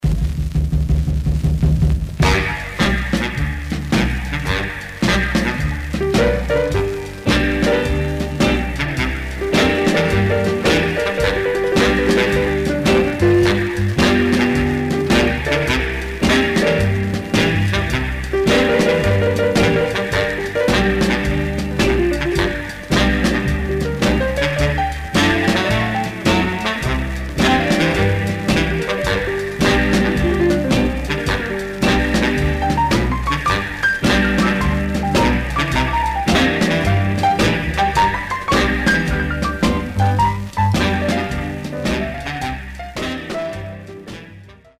Stereo/mono Mono
R&B Instrumental Condition